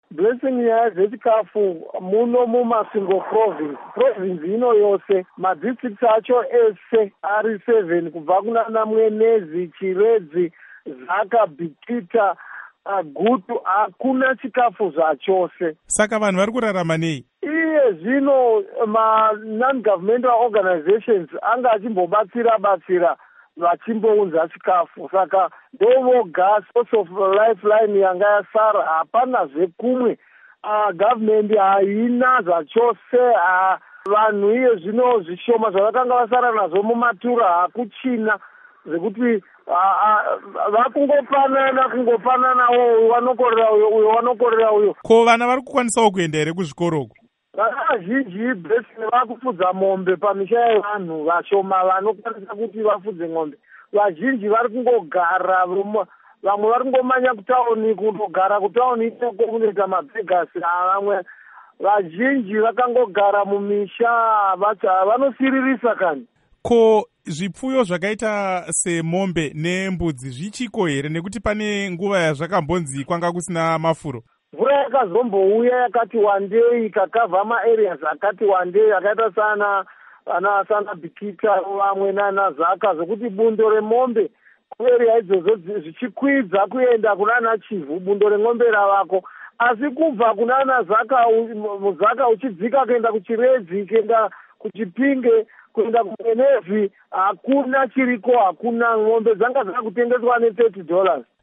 Hurukuro naVaMisheck Marava